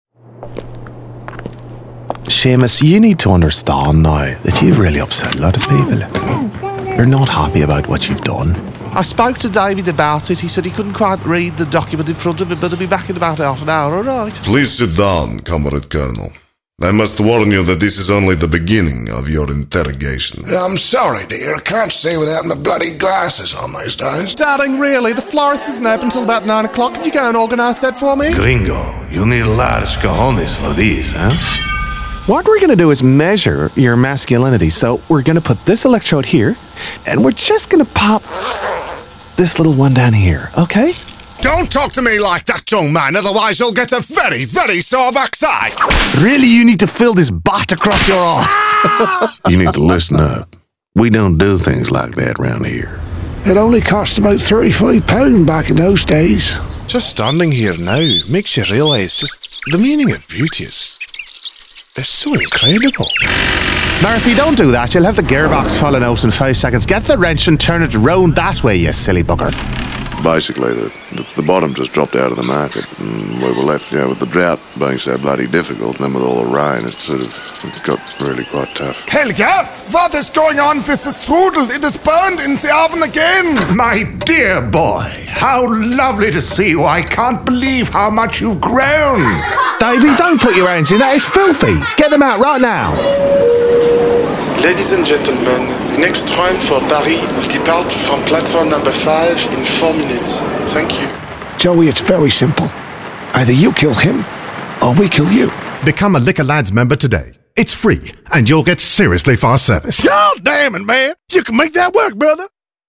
Male Voice Over Artist with RMK Voices Sydney
If you’re chasing a heightened sense of warmth, intelligence, clarity, calmness, and understated nobility that oozes luxury …
Great sounding voice, always a pleasure to record/ direct and just an all round nice bloke.